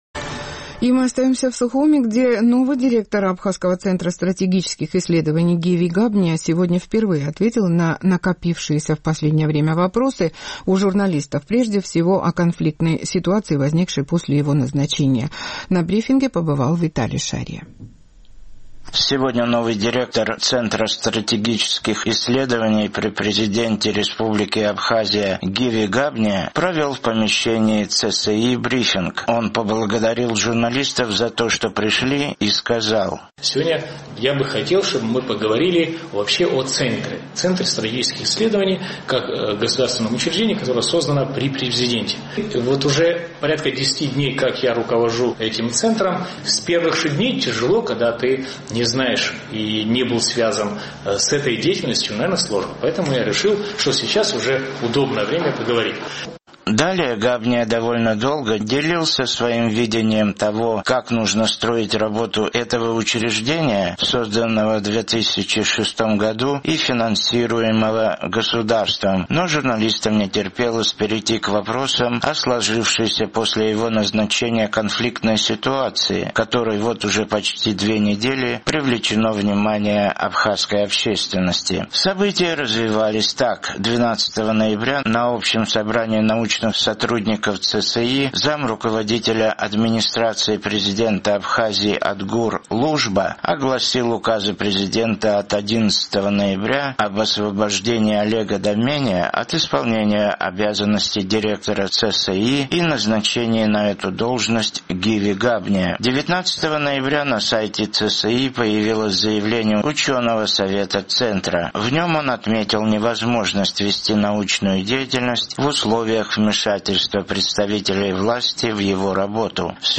Сегодня новый директор Центра стратегических исследований при президенте РА Гиви Габниа впервые после своего назначения встретился с журналистами: